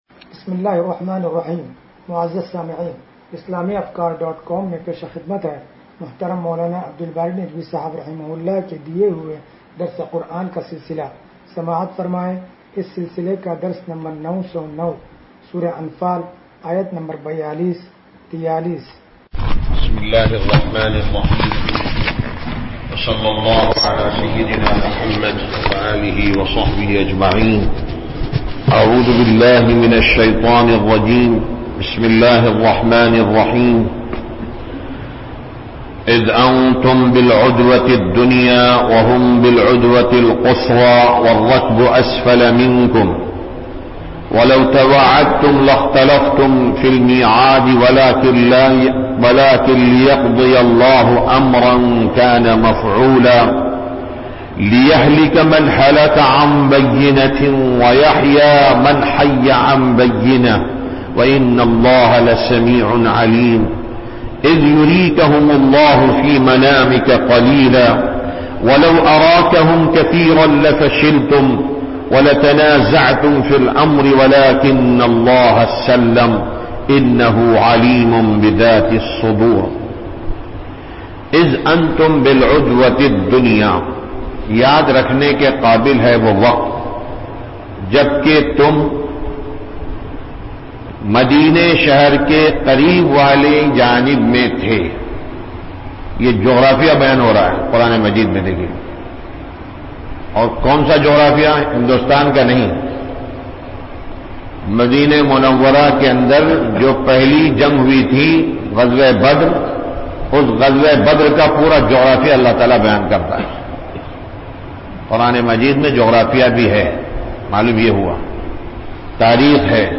درس قرآن نمبر 0909